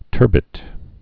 (tûrbĭt)